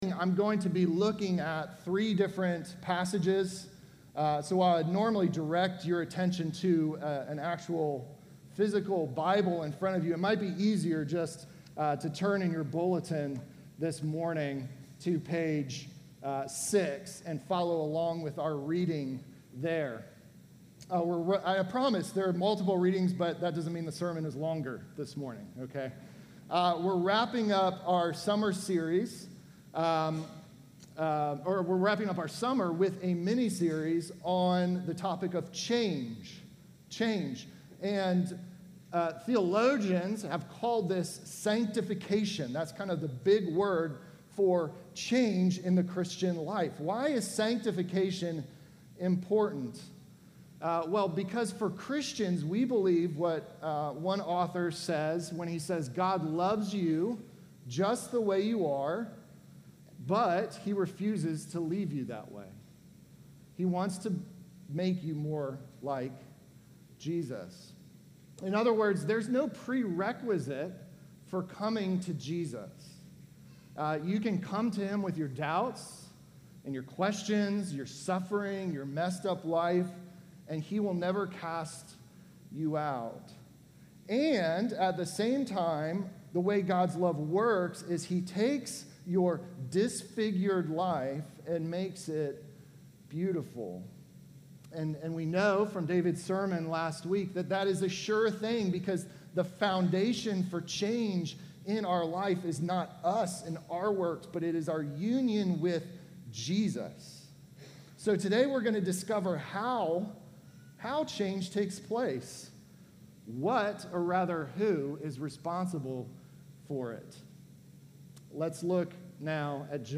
Sermon from August 3